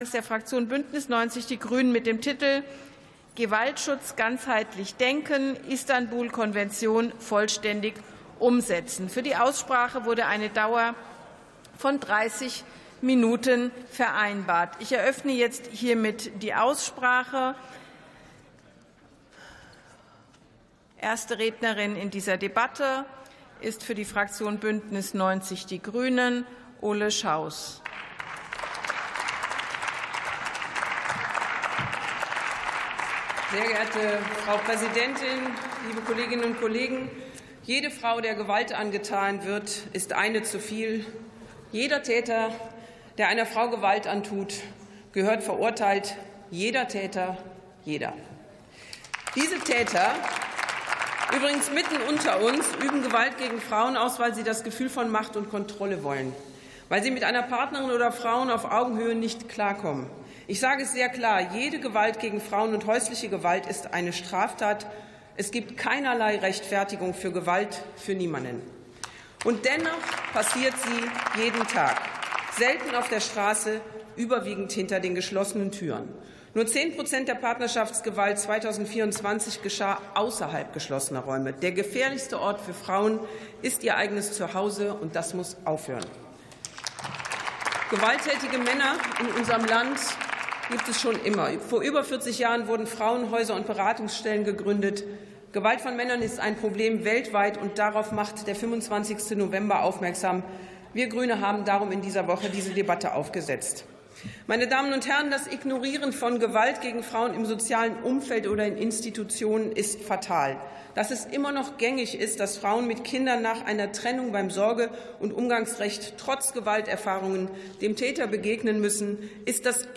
Plenarsitzungen - Audio Podcasts